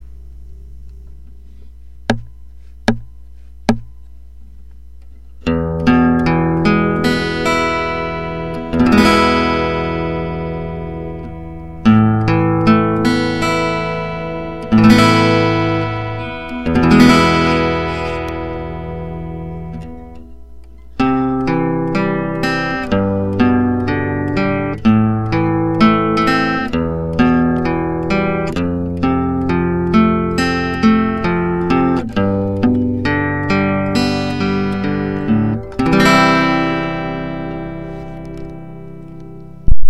・ギターに２つ貼る
・録音はピエゾ→自作のプリ→ミキサ→USBオーディオの変換器でライン入力→パソコン
ピエゾ_1_5-5-8
音量調整で音の変化があるので、まあまあ成功